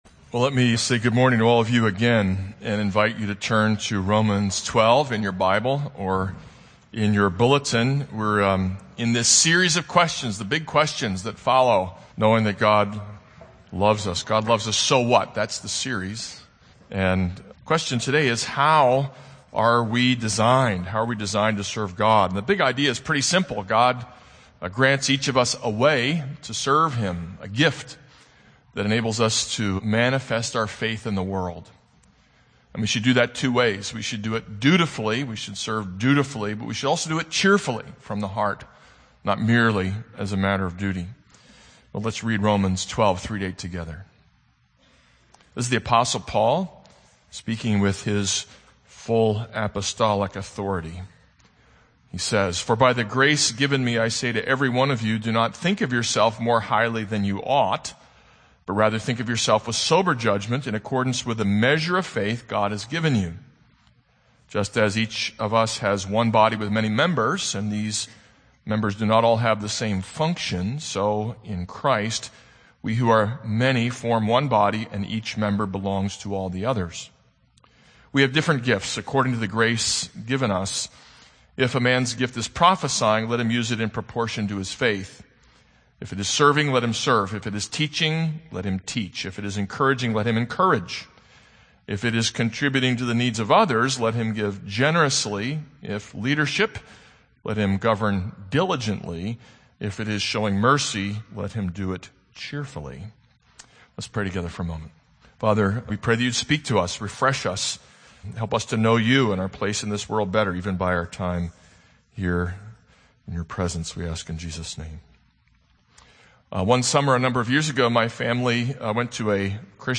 This is a sermon on Romans 12:3-8.